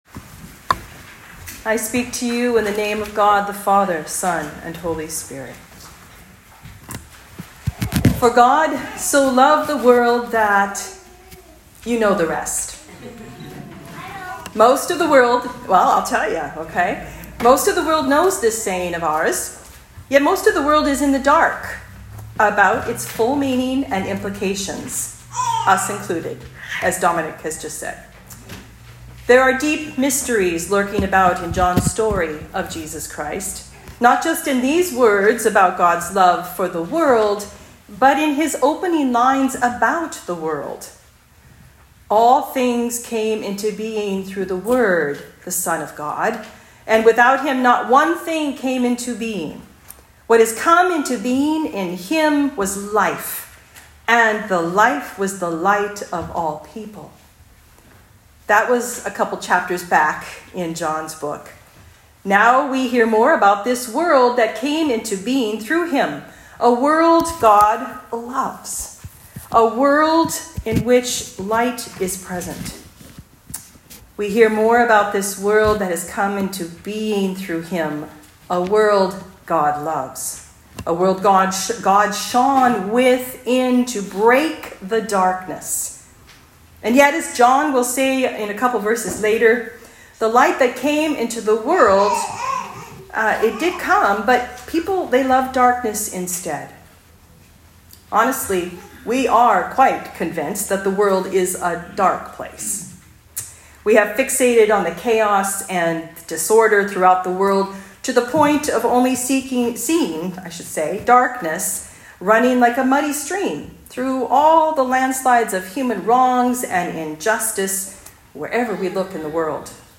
Sermons | Holy Trinity North Saanich Anglican Church
Talk on John 3.16